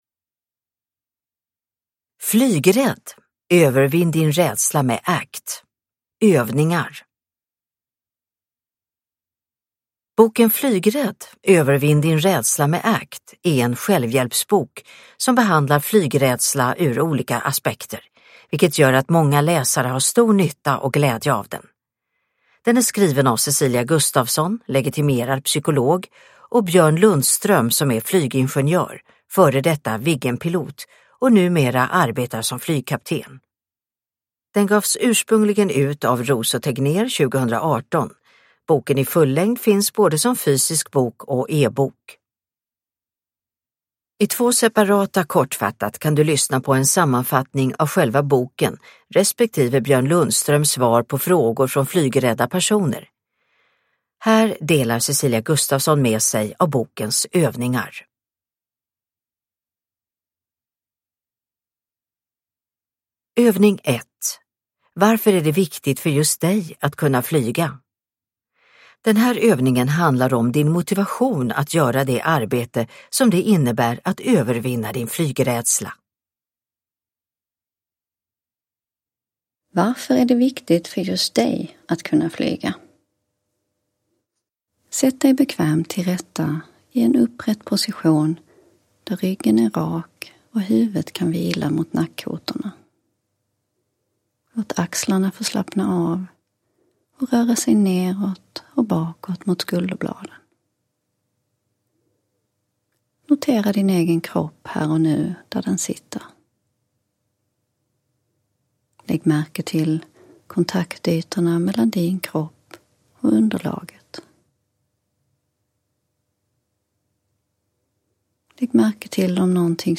Flygrädd - Övningar – Ljudbok – Laddas ner